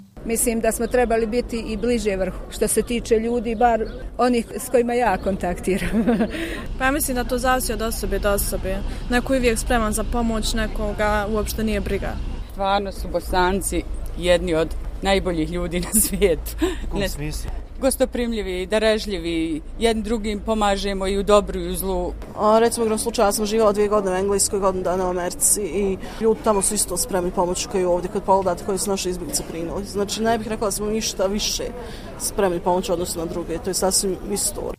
Građani koje smo anketirali slažu se sa ovom ocjenom: